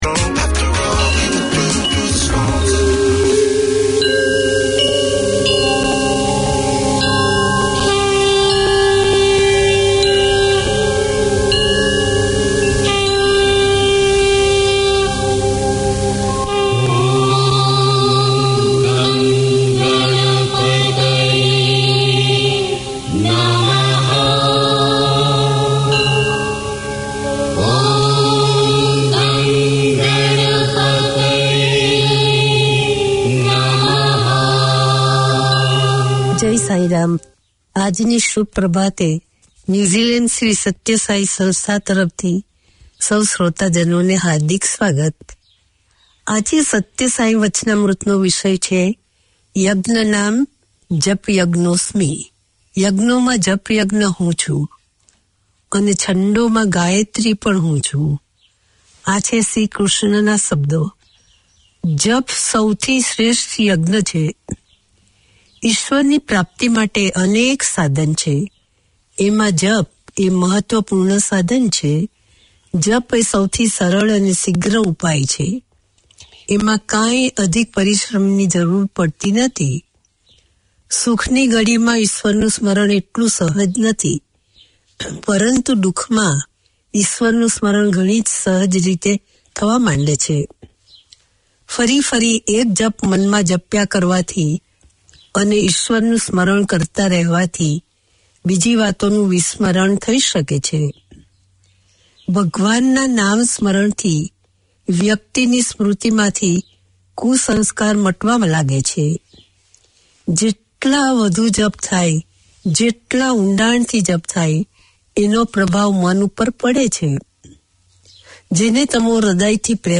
Radio made by over 100 Aucklanders addressing the diverse cultures and interests in 35 languages.
Each week Sai Baba devotees can pause for ten minutes to consider the teachings of Satya Sai Baba and hear devotional songs.